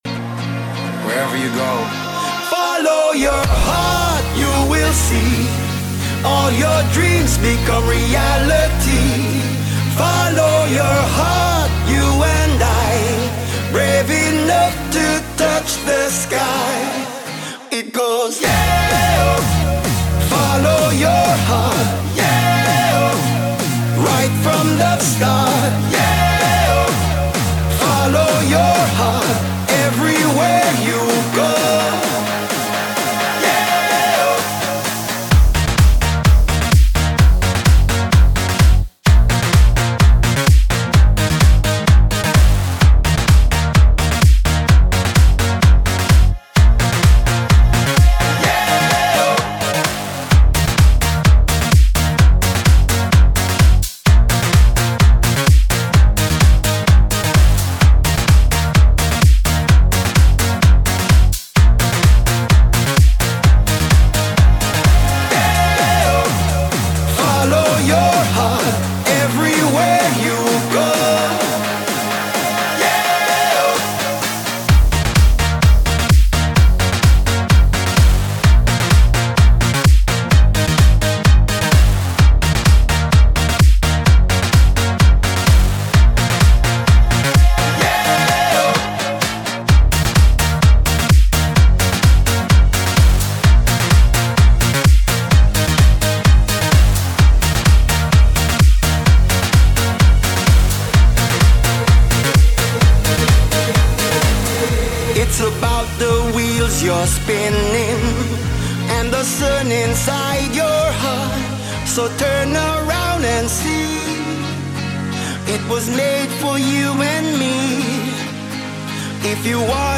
Жанр: Electro House